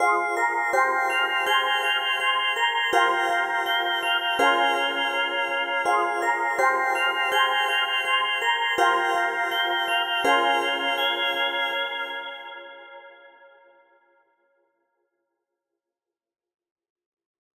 blockade_164BPM.wav